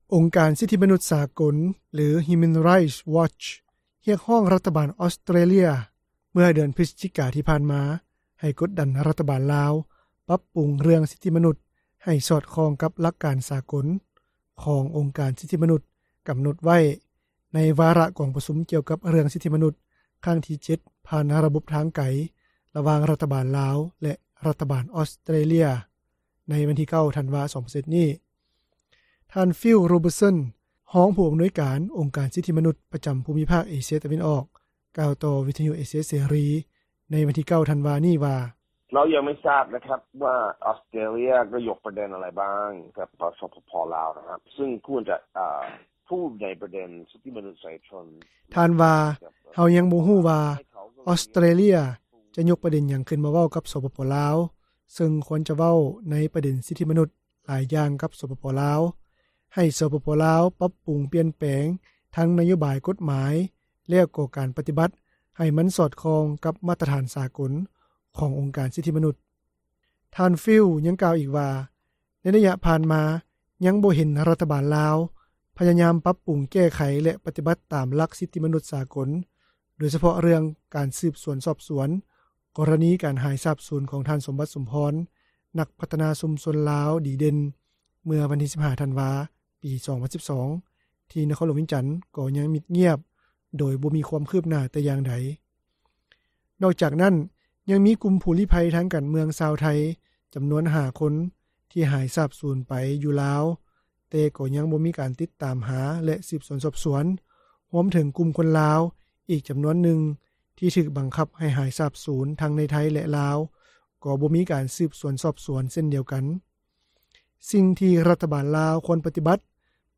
ຊາວລາວຜູ້ນຶ່ງ ໃນນະຄອນຫຼວງຈັນ ກ່າວຕໍ່ ວິທຍຸເອເຊັຽເສຣີ  ໃນວັນທີ 9 ທັນວານີ້ວ່າ:
ຊາວລາວຜູ້ນຶ່ງ ທີ່ເຮັດວຽກກັບອົງການ ທີ່ບໍ່ຂຶ້ນກັບ ຣັຖບານ ຫຼື (NGO) ກ່າວຕໍ່ວິທຍຸເອເຊັຽເສຣີ ໃນວັນທີ 9 ທັນວາ ນີ້ວ່າ: